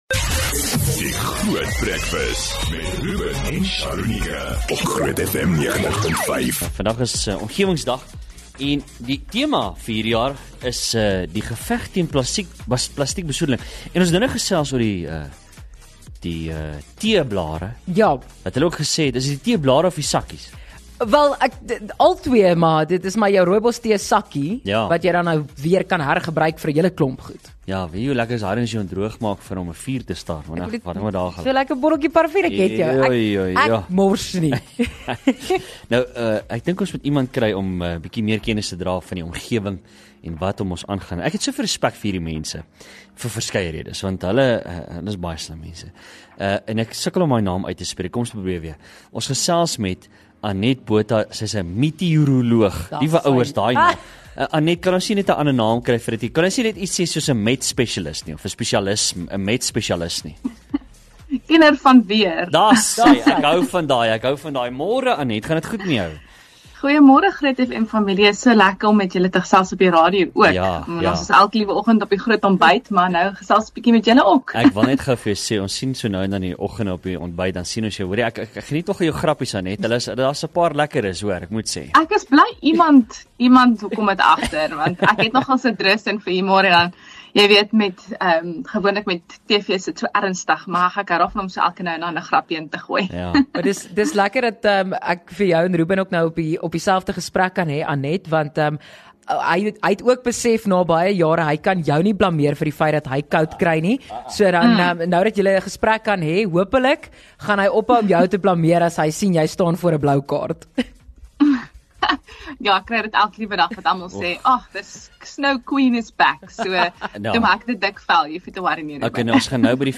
Luister gerus weer hier na die gesprek as jy dit gemis het.